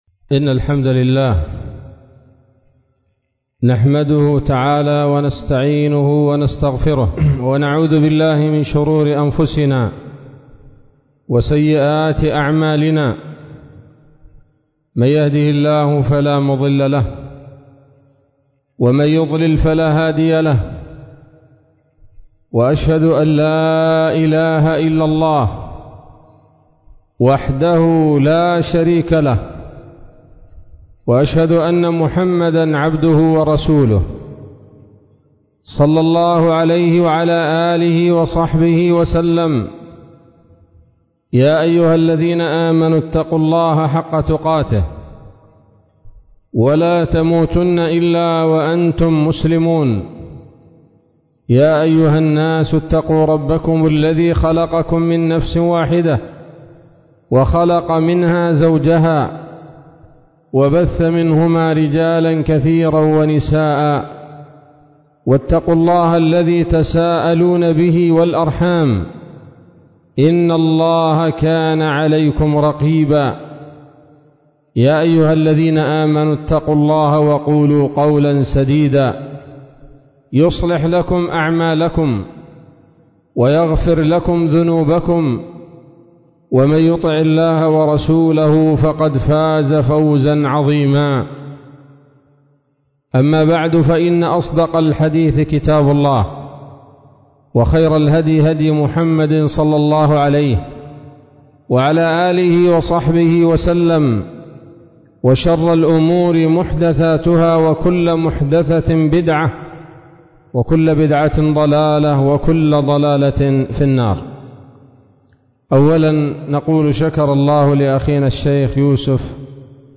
محاضرة-خيانة-الأوطان.mp3